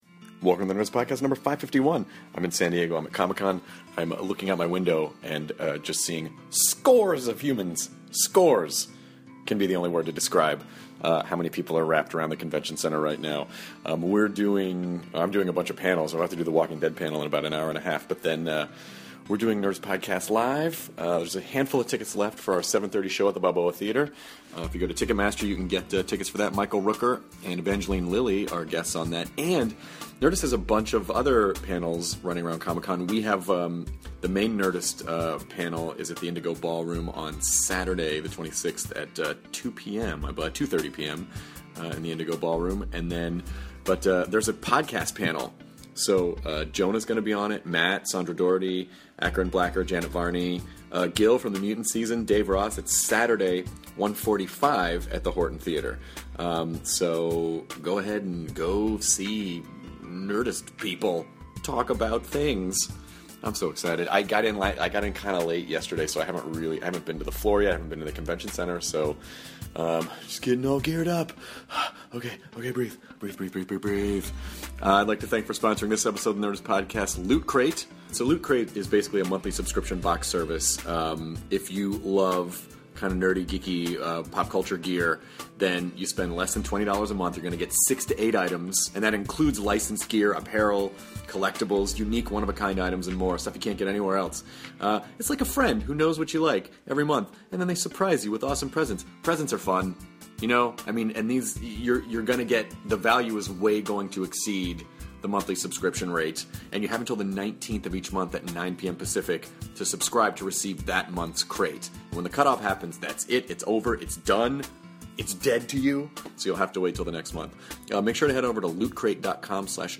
Actor/filmmaker Eli Roth sits down with Chris and Jonah to talk about the importance of release dates for horror films, doing his own Milgram experiment, how babysitting prepared him to be a director and the new season of Hemlock Grove!